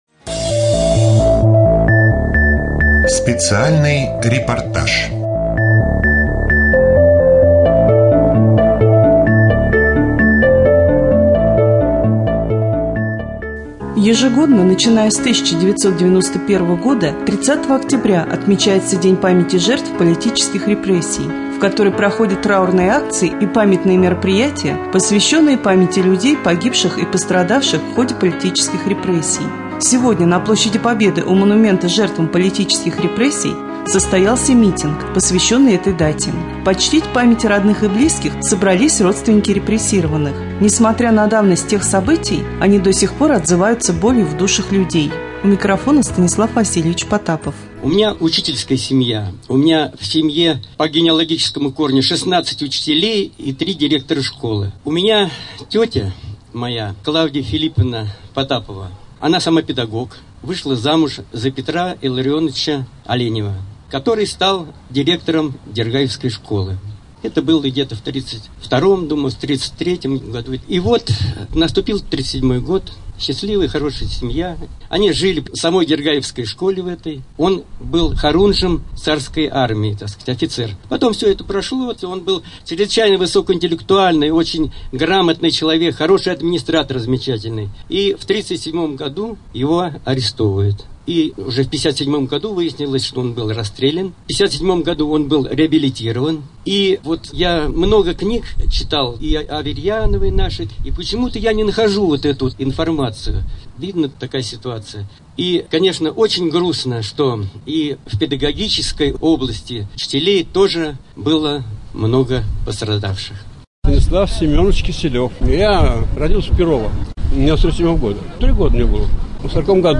3.Рубрика «Специальный репортаж ». На площади Победы прошел митинг, посвященный памяти жертв политических репрессий.
3.Митинг.mp3